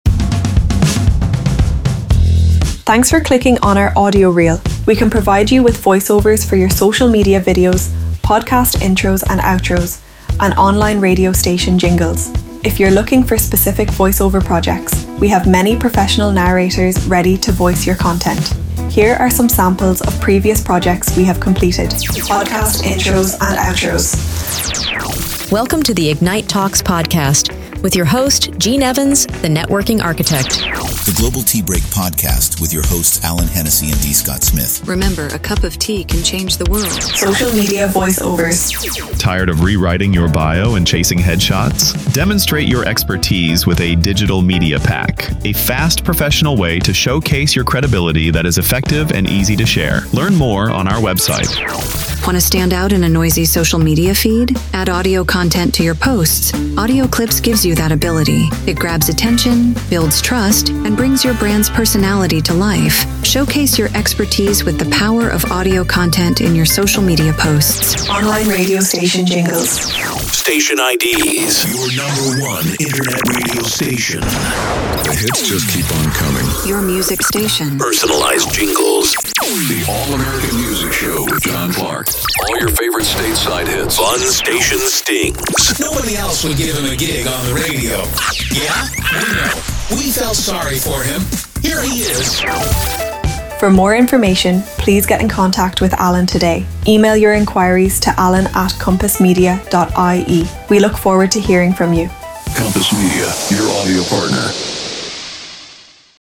• Podcast Intros & Outros: Set the tone and leave a lasting impression with branded audio that reinforces identity and builds listener loyalty.
• Online Radio Station Jingles: Promote your station with catchy, professional jingles that enhance listener experience and brand recall.
Kompass Media Audio Showreel including samples of Previous Projects